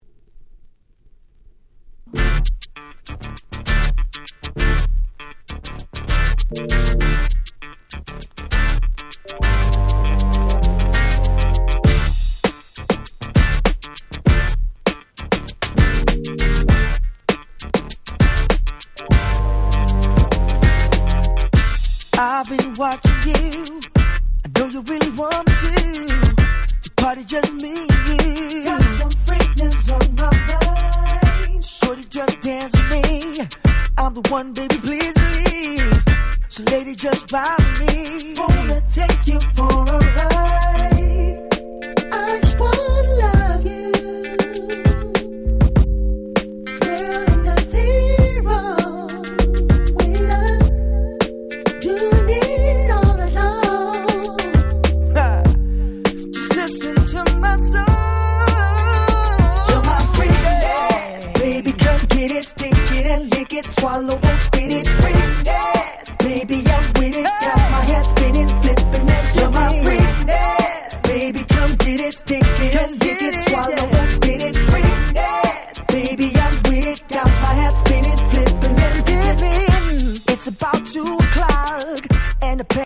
04' Super Nice R&B♪